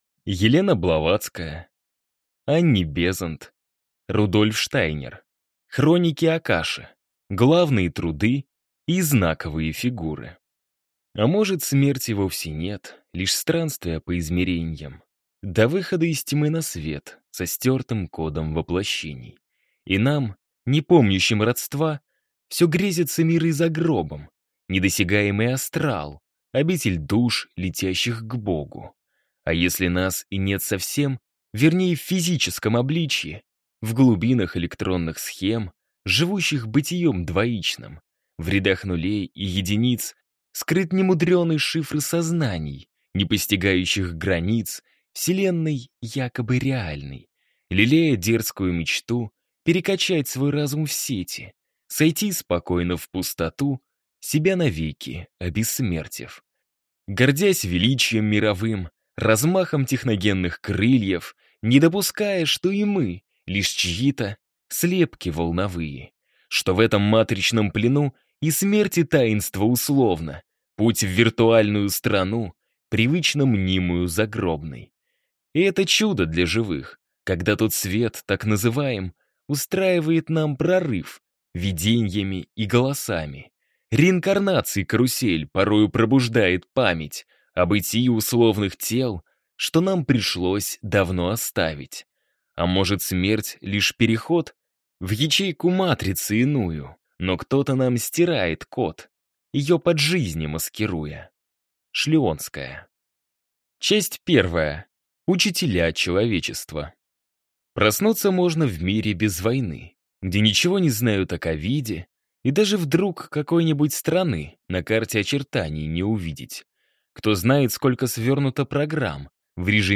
Аудиокнига Хроники Акаши: главные труды и знаковые фигуры | Библиотека аудиокниг